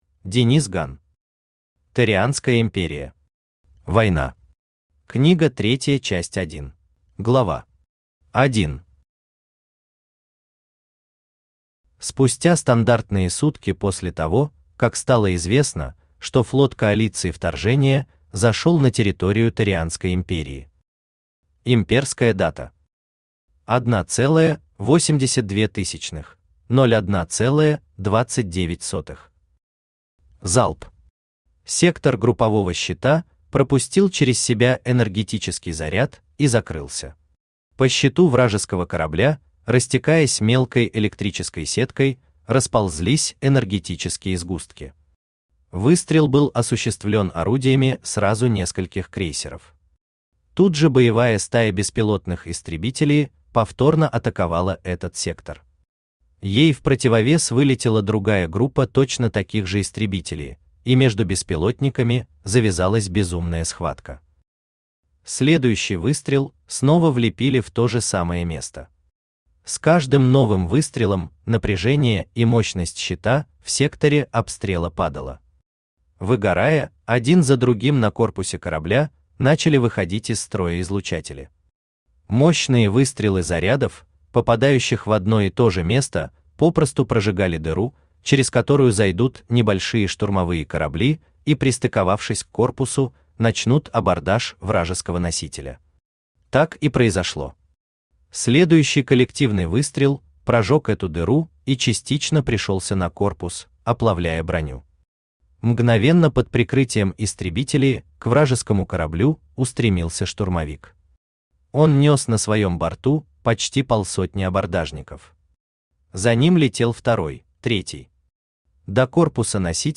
Аудиокнига Торианская империя. Война. Книга 3 Часть 1 | Библиотека аудиокниг
Aудиокнига Торианская империя. Война. Книга 3 Часть 1 Автор Денис Ган Читает аудиокнигу Авточтец ЛитРес.